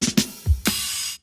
British SKA REGGAE FILL - 03.wav